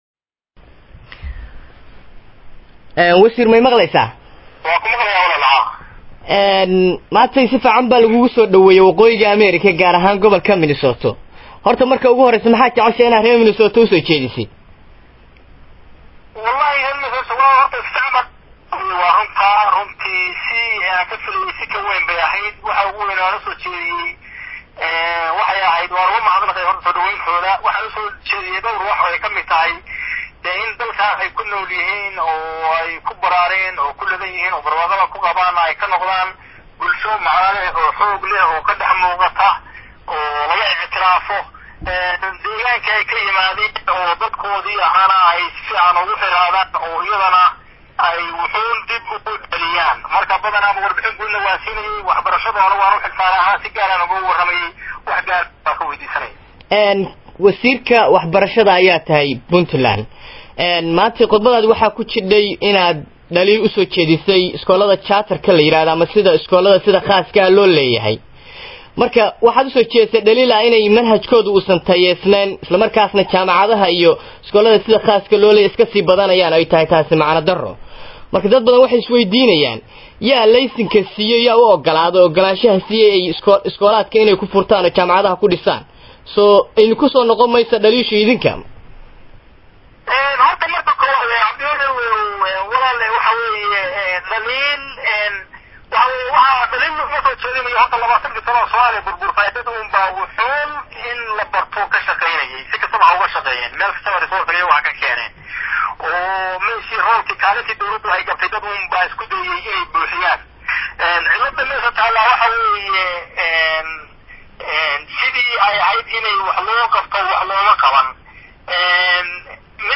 Waraysiga Wasiirka Waxbarashada DGPL